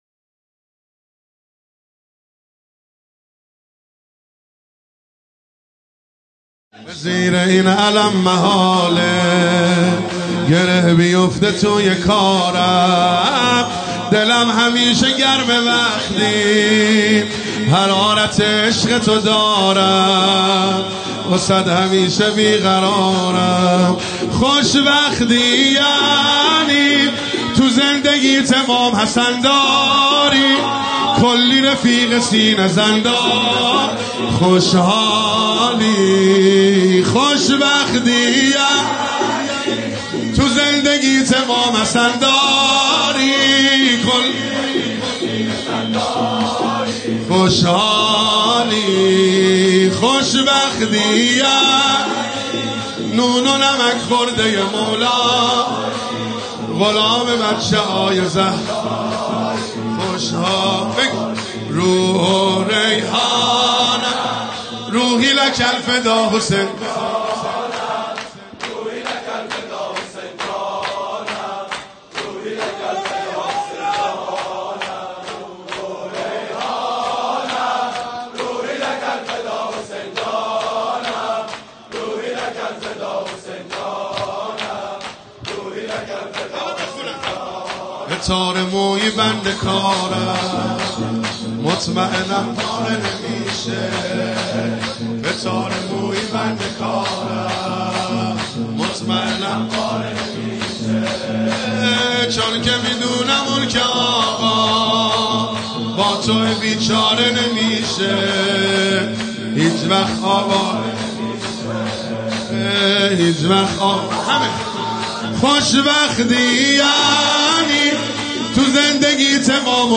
شور | شب ششم محرم 1397 | هیأت یافاطمه الزهرا (سلام الله علیها) بابل